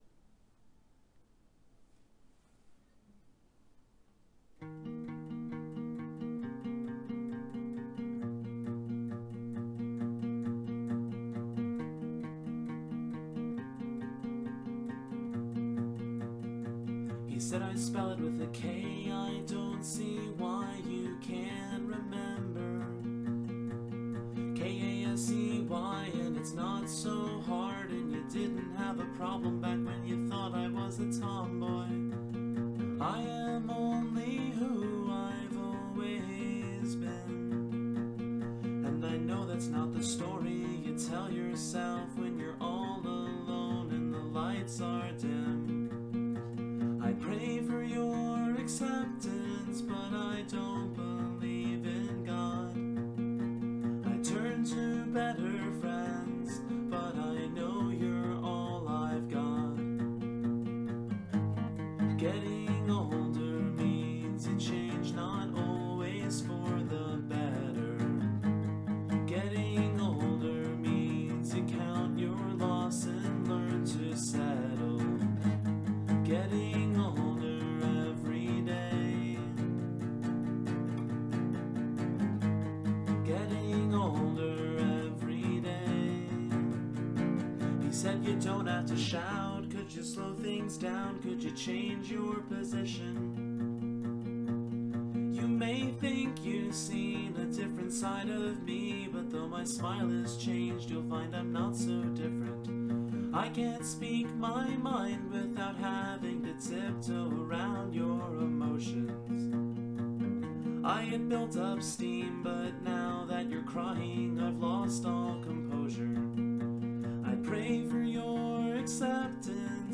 This song is haunting in its beauty.